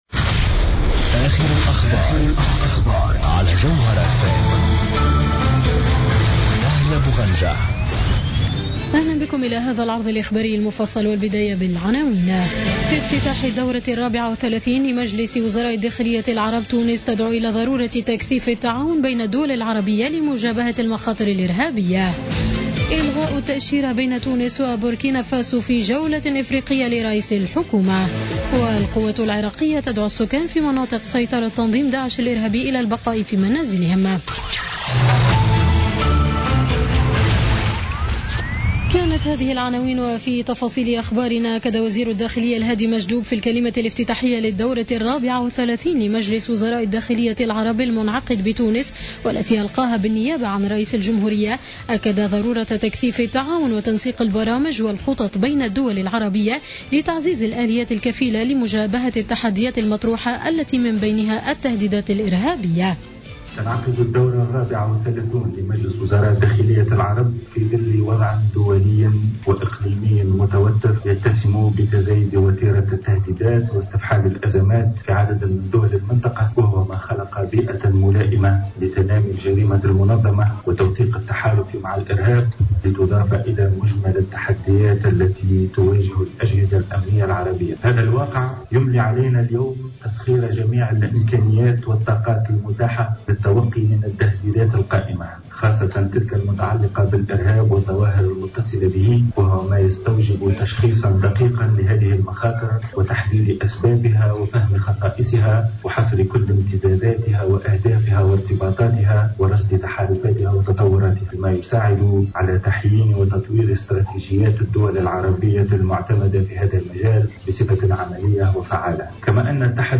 نشرة أخبار السابعة مساء ليوم الأربعاء 5 أفريل 2017